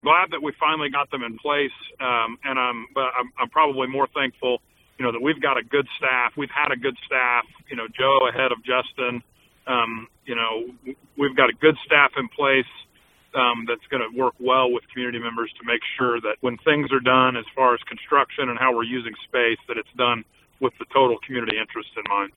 City Commissioner Jamie Sauder spoke with KVOE News recently saying the approval of the regulations was “long overdue.”